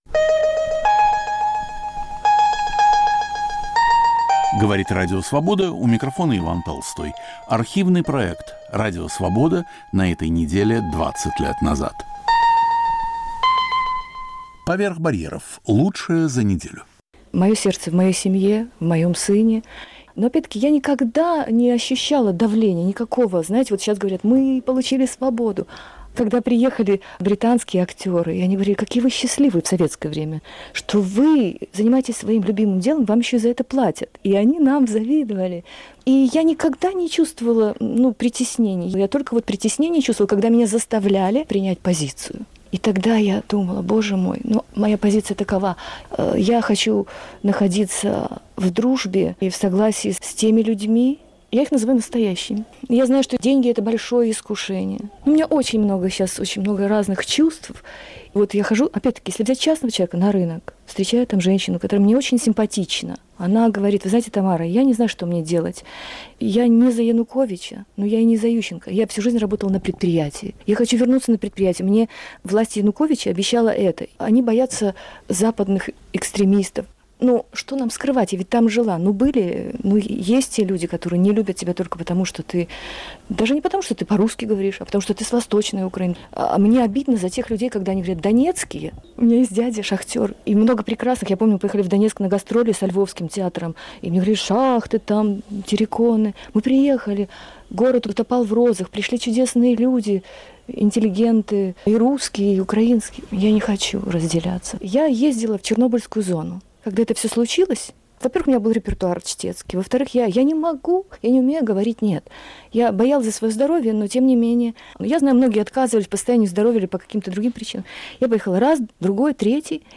Александр Генис о столетнем юбилее изготовителя мотоциклов "Харлей Дэвидсон". Редактор и ведущий Алексей Цветков.